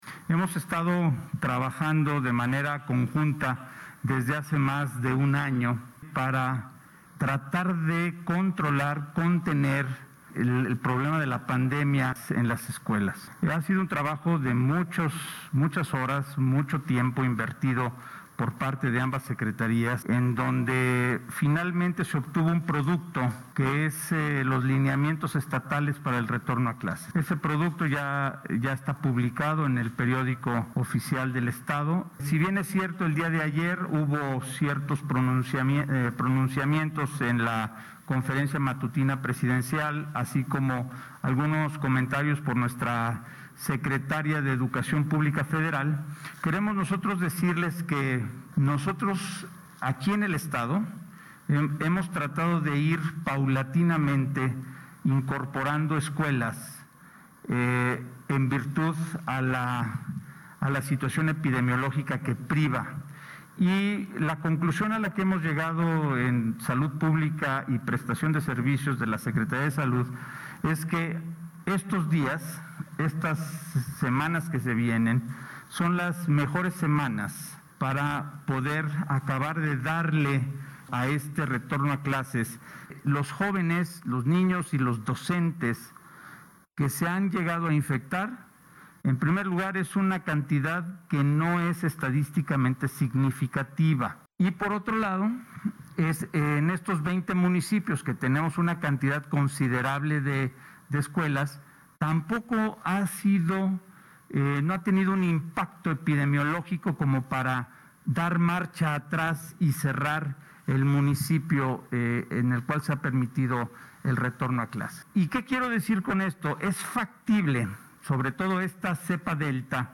Pachuca, Hidalgo., a 14 de octubre de 2021.- El porcentaje de la población vacunada en Hidalgo a la fecha indica un 59 por ciento de personas que ya cuentan con esquema completo, es decir más de 2 millones 192 mil dosis aplicadas, sin embargo, debido a lo observado en las últimas 7 semanas es probable que en Hidalgo se haya alcanzado una inmunidad mixta o hibrida,  por lo que se está entrando en una etapa de descenso, aseguró el titular de la Secretaría de Salud en Hidalgo (SSH), Alejandro Efraín Benítez Herrera, al detallar mediante conferencia de prensa, el panorama epidemiológico y estrategia de vacunación así como protocolos para retorno a clases ante la pandemia por COVID19.
Alejandro-Efrain-Benitez-Herrera-2.mp3